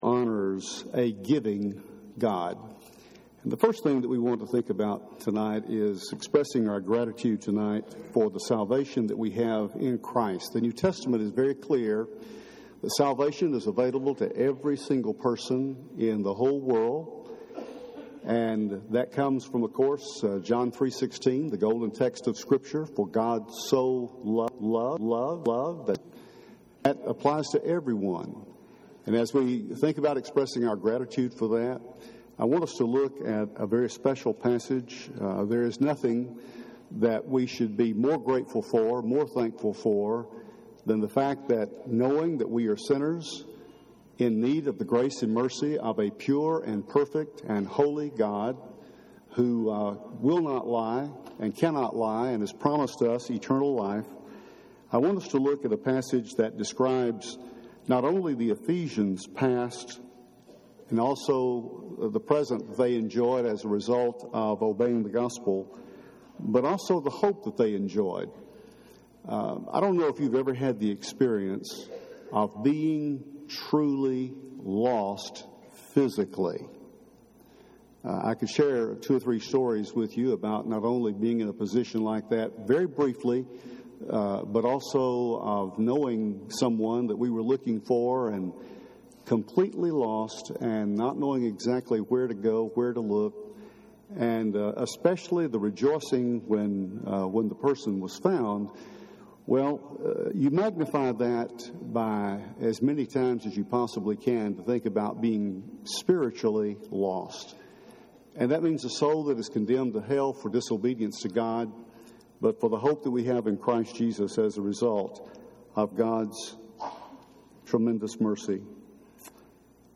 Tonight, we continue our anticipation of Thanksgiving with a sermon in song. Let’s rekindle our gratitude to God for every spiritual and material blessing He continually gives.